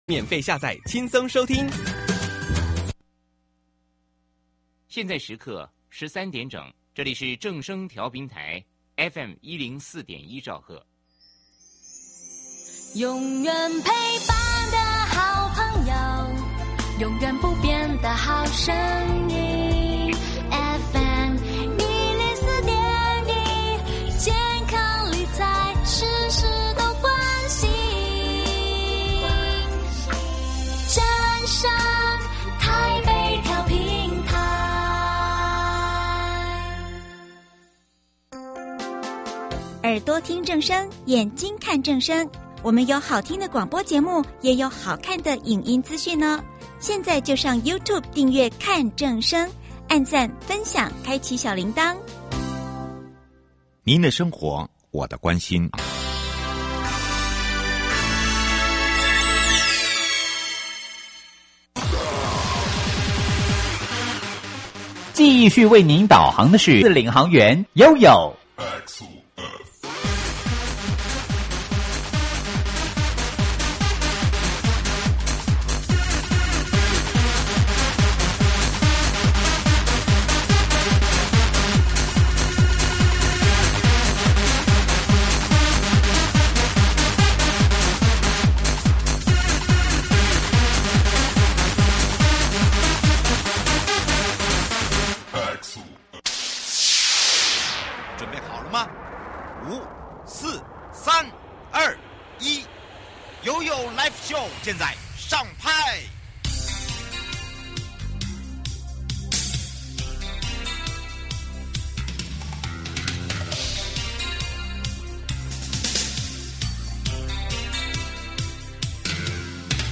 受訪者： 營建你我他 快樂平安行~七嘴八舌講清楚~樂活街道自在同行!(一) 市區道路養護管理暨人行環境無障 礙